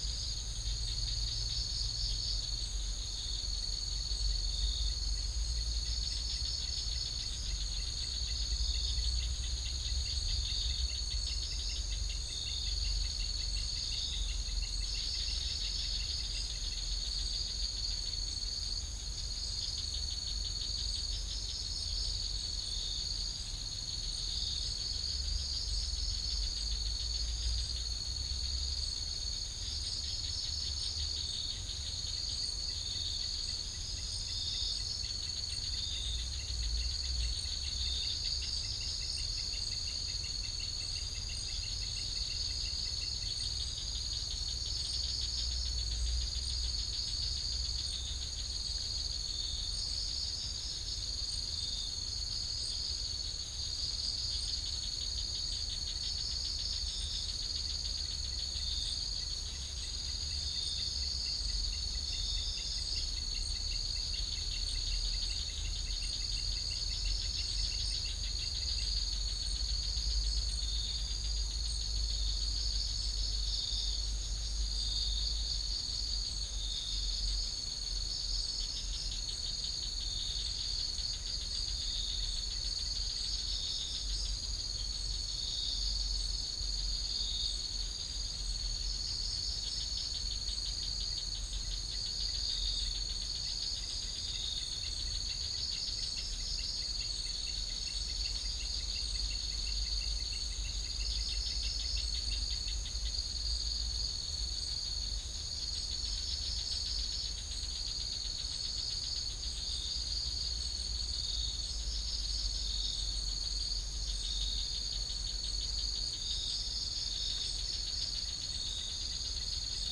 Spilopelia chinensis